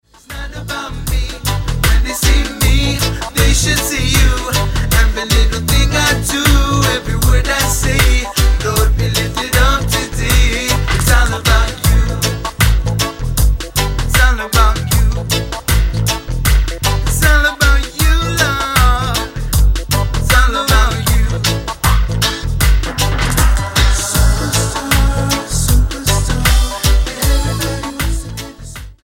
STYLE: Reggae
Tonga gospel reggae rules!
near perfect pop reggae concoction